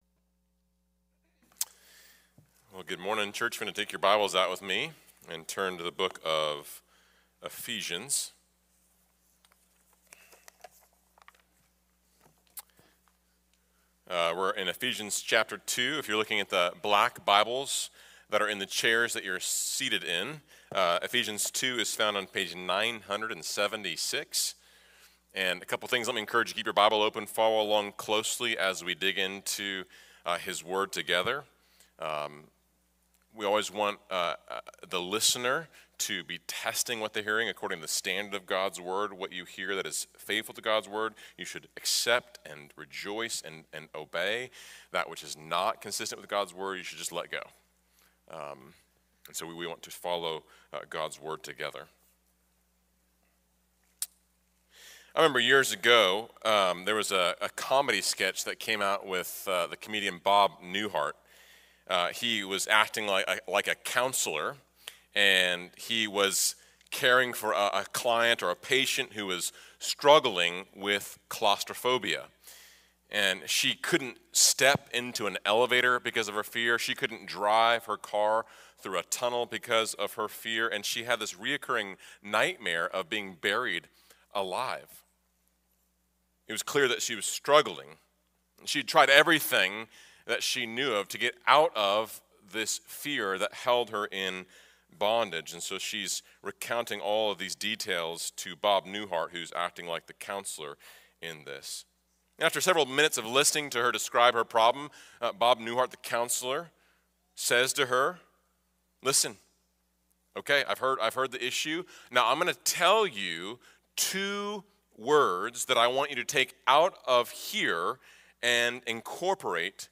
A message from the series "Behold our God!." Psalm 115 Introduction There are many who have suffered significant trauma in life.